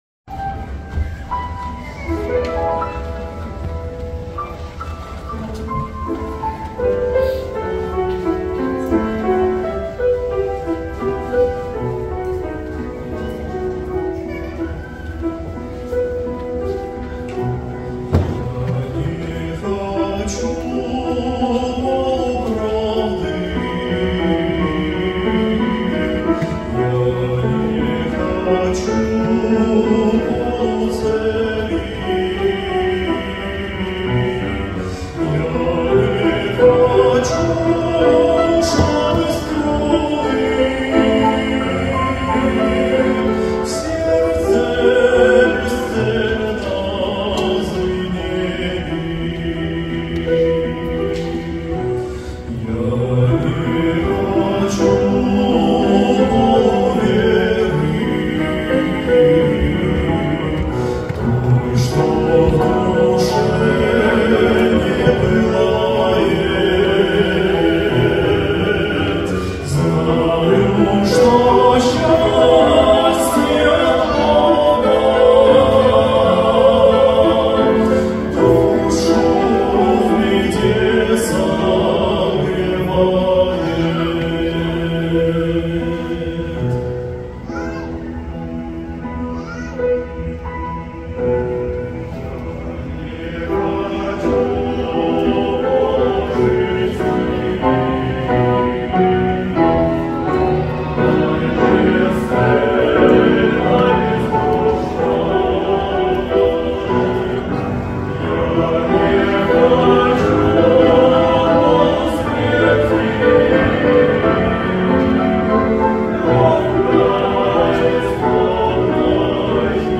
184 просмотра 294 прослушивания 5 скачиваний BPM: 75